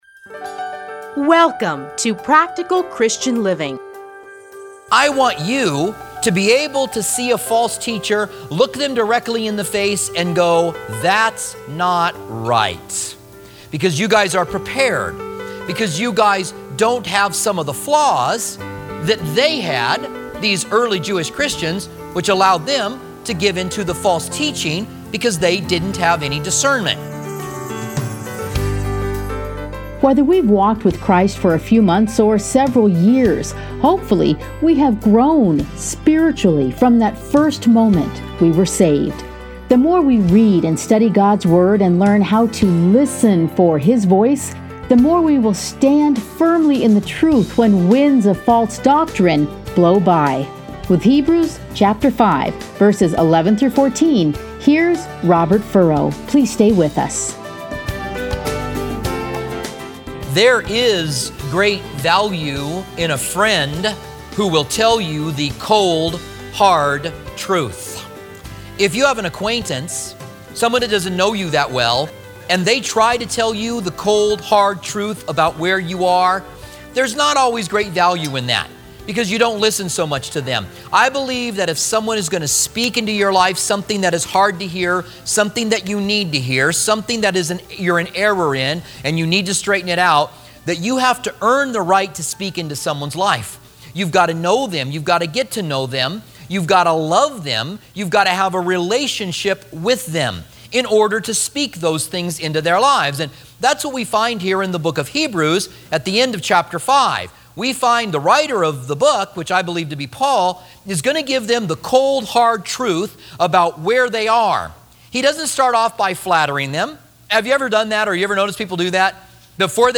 Listen here to a teaching from Hebrews.